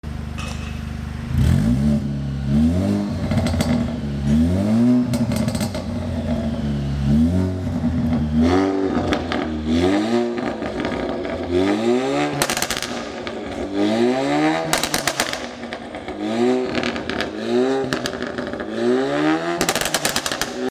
Downpipe Y Final Recto + Sound Effects Free Download